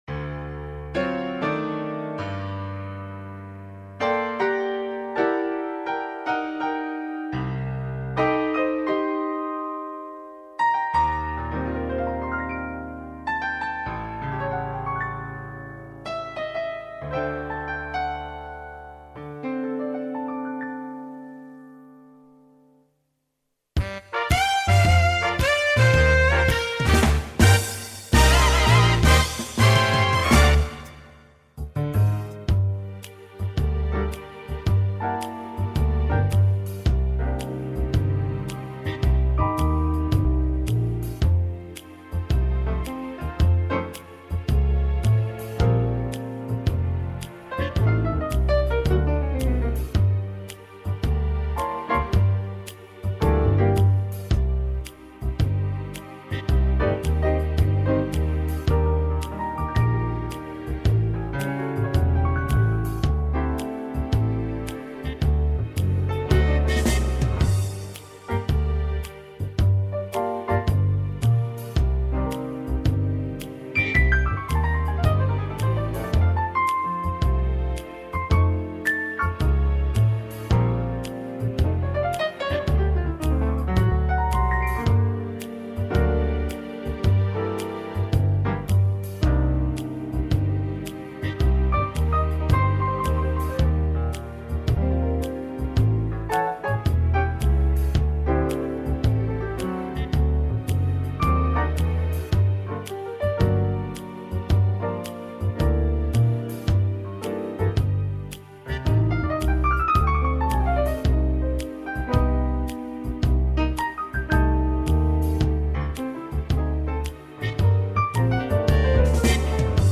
минусовка версия 32103